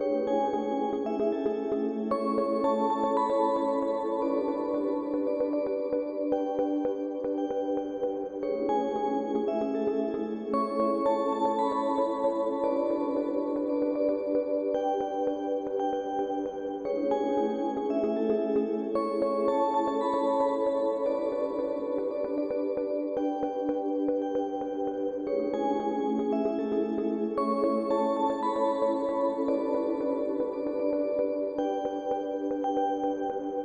A seamless heavenly / dreamy sound loop.